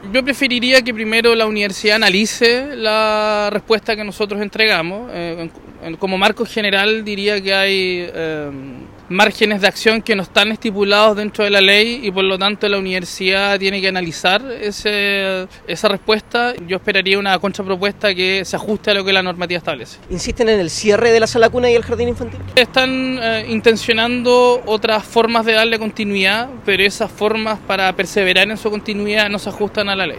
Consultado por La Radio, el Seremi Juan Pablo Gerter, declinó profundizar en el detalle de la propuesta, pero declaró que este nuevo intento de las autoridades de la universidad tampoco se ajusta a la ley.